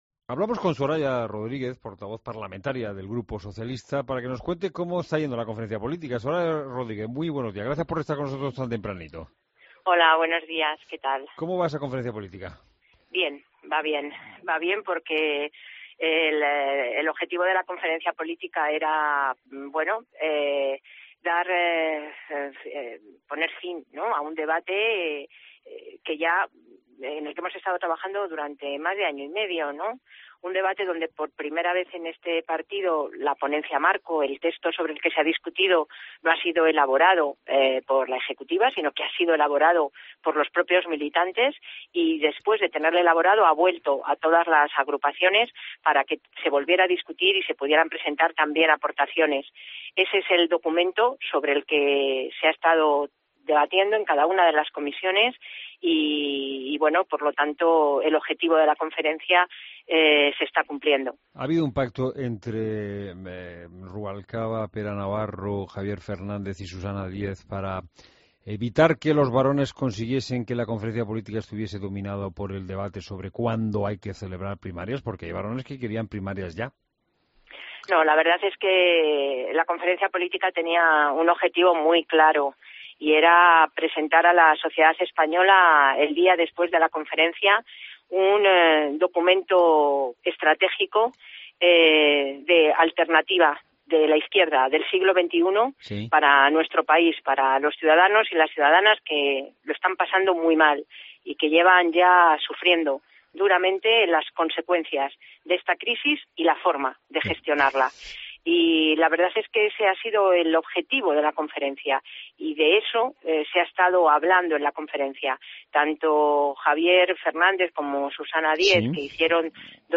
Entrevista a Soraya Rodríguez en La Mañana de COPE